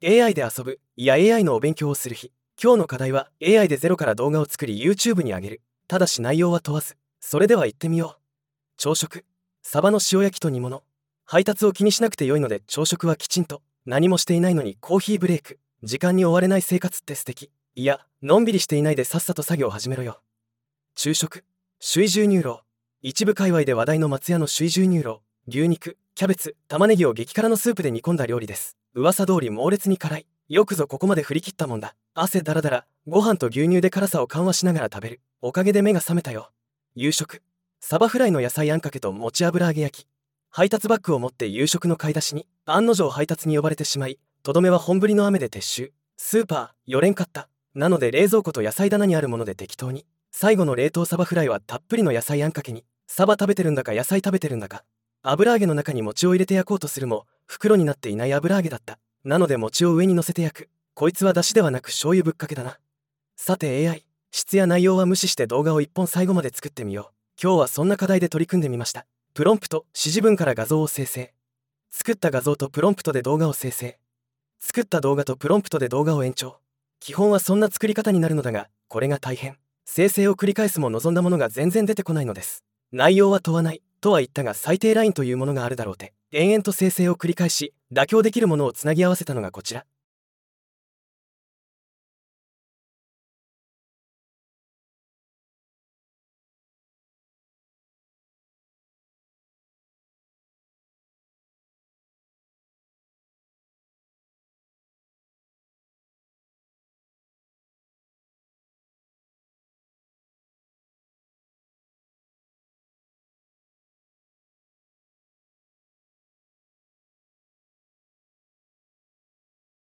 なお今回は静止画/動画/音声/効果音/音楽、全てAIで生成しています。